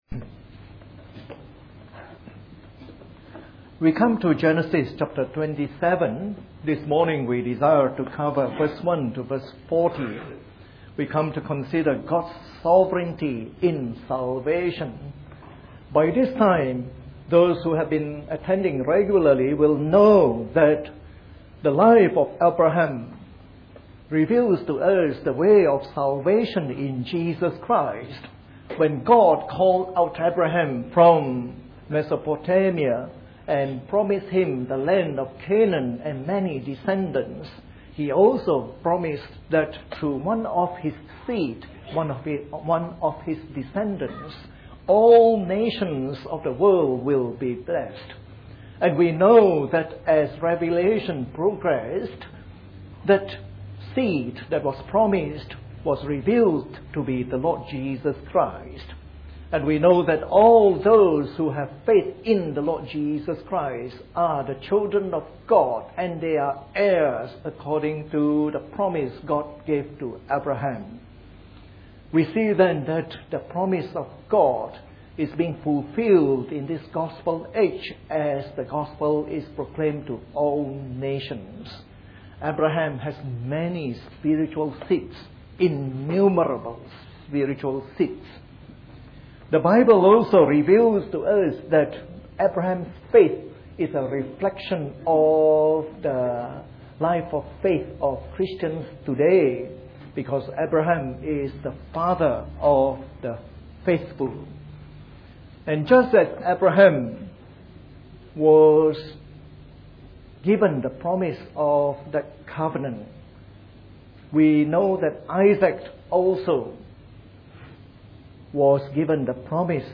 Part of our series on the Book of Genesis delivered in the Morning Service.